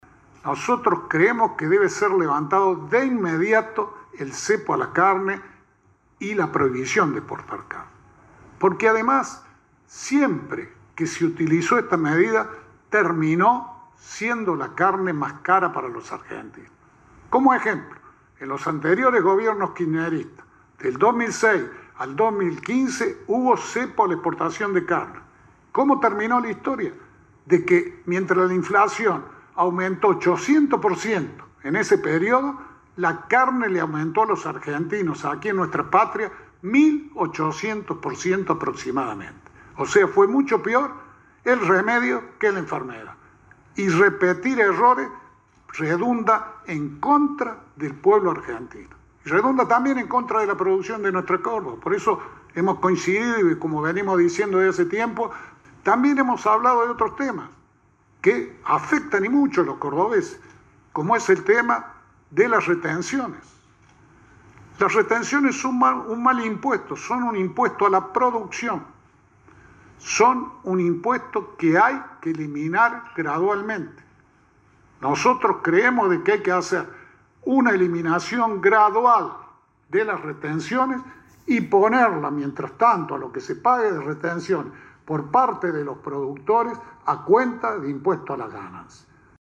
Durante su discurso, Schiaretti manifestó su descontento frente a este impuesto ya que, en los últimos 14 años, la Provincia aportó dos billones 700 mil millones de pesos.